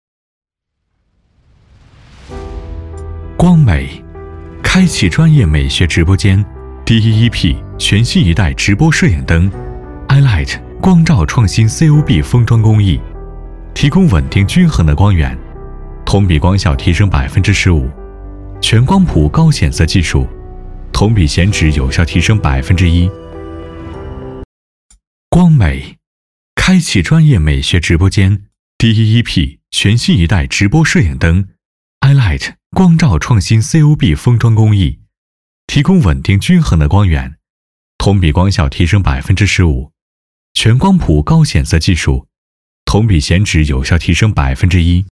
光美（广告 产品介绍）